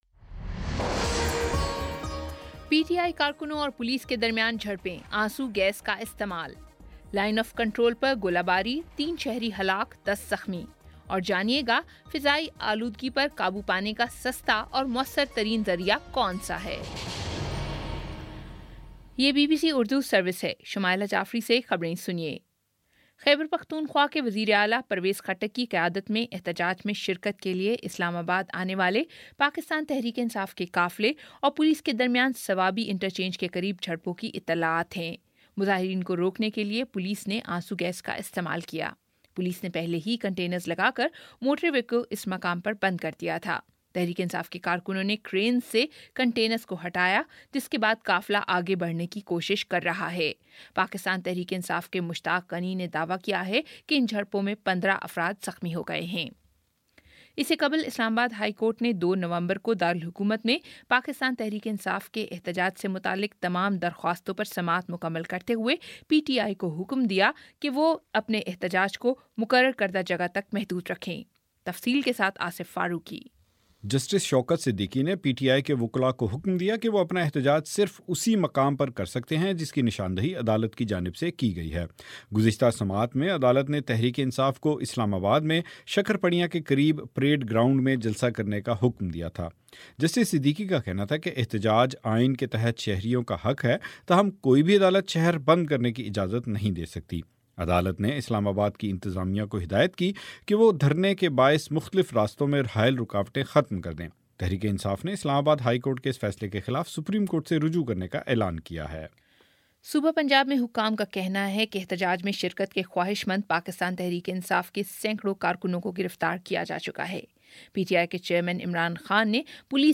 اکتوبر31 : شام سات بجے کا نیوز بُلیٹن